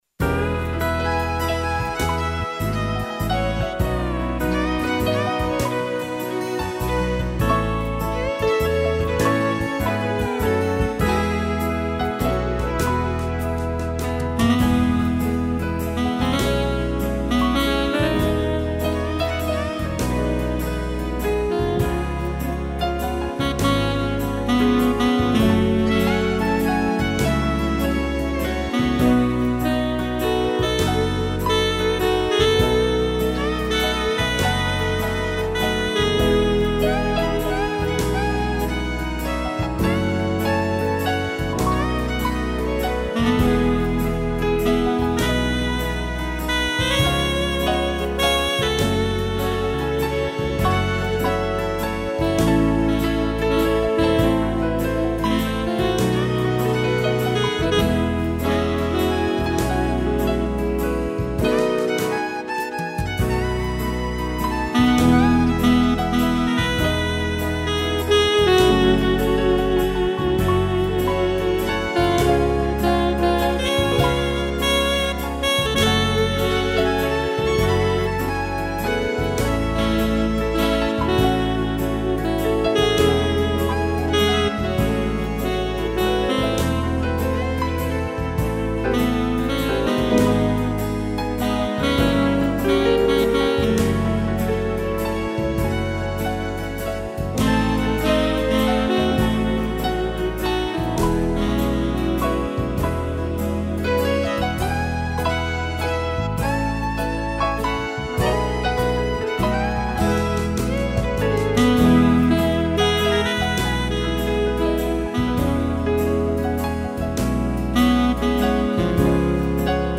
arranjo solo sax
piano e violino